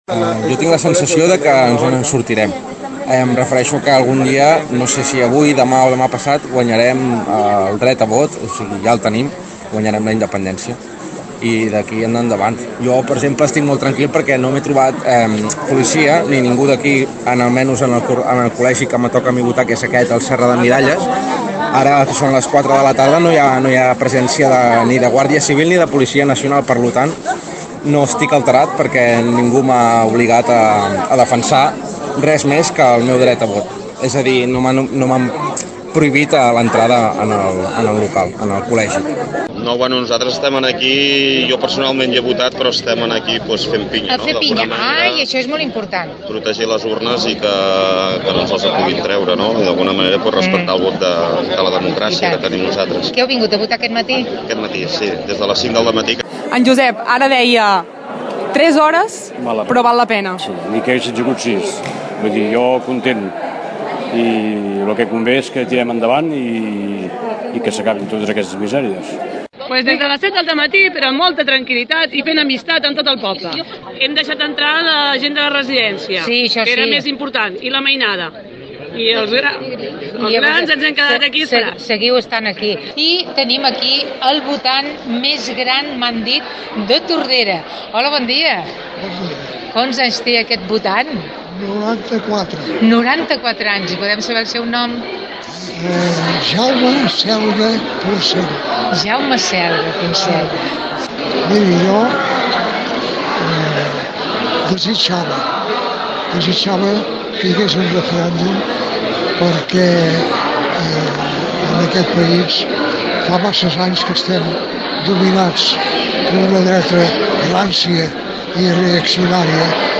Centenars de veïns i veïnes del poble van omplir durant tot el dia els col·legis electorals. L’equip de ràdio tordera va parlar amb molts d’ells i així ens valoraven la jornada.